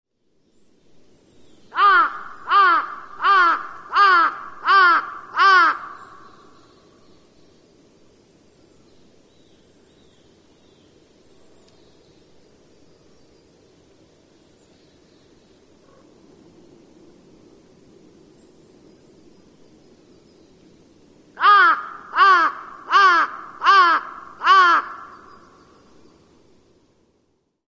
hashibutogarasu_c1.mp3